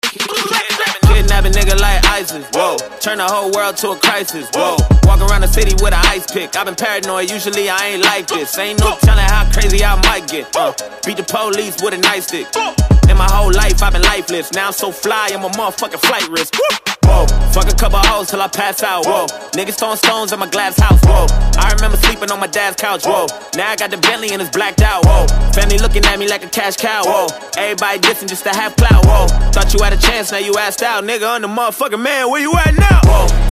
Ringtones Category: Rap - Hip Hop